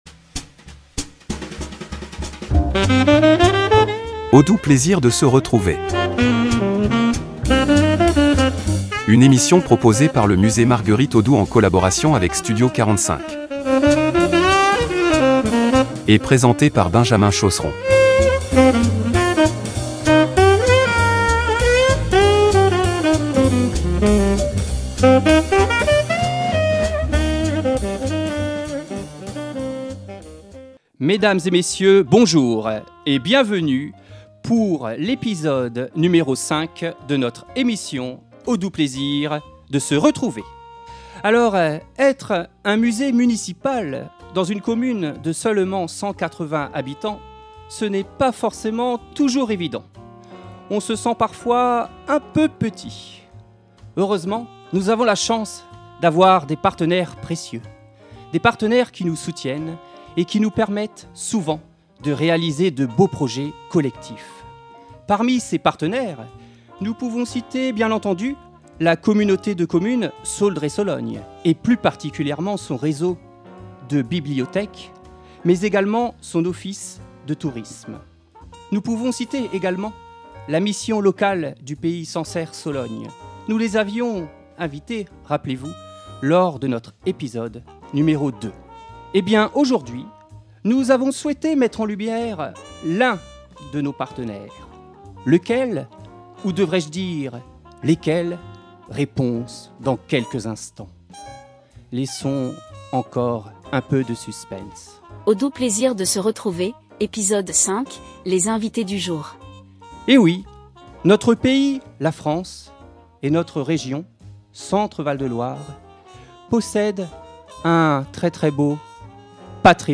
Un échange riche pour explorer la manière dont notre pays célèbre et transmet l’héritage de ses grands auteurs.